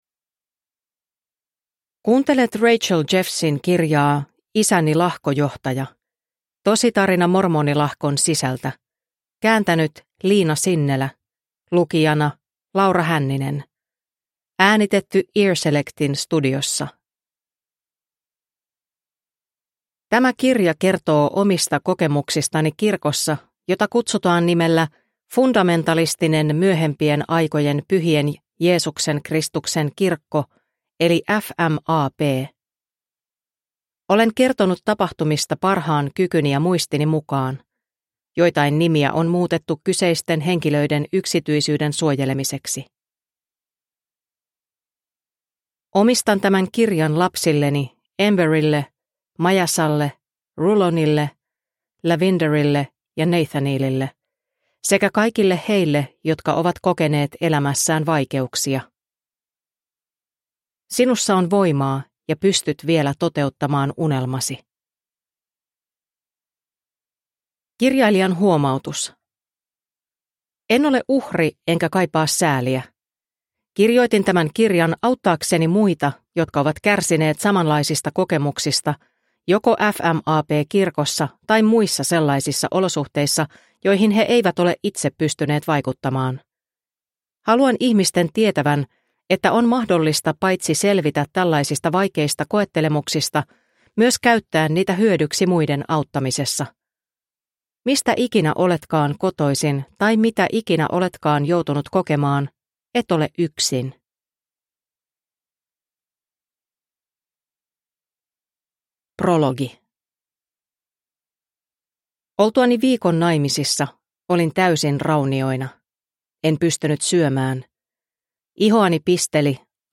Isäni lahkojohtaja – Ljudbok – Laddas ner